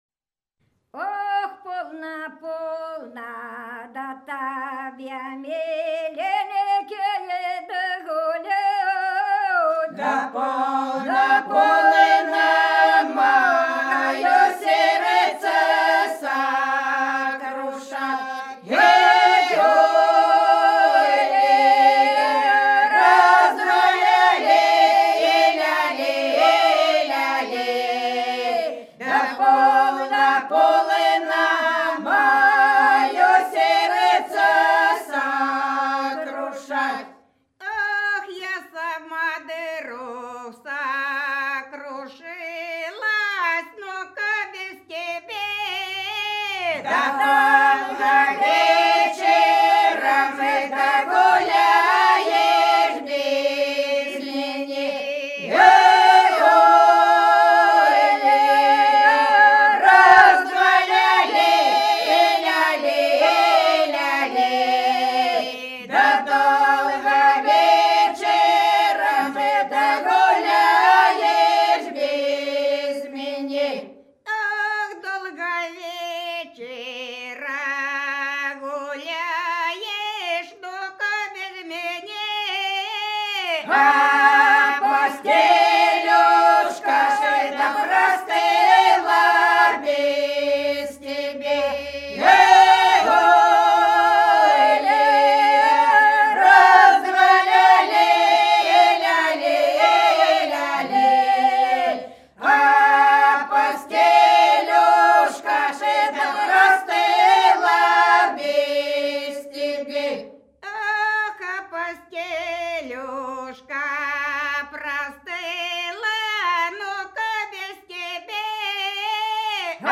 Хороша наша деревня Полно, полно, тебе миленький, гулять - протяжная (с. Глуховка)
17_Полно,_полно,_тебе_миленький,_гулять_-_протяжная.mp3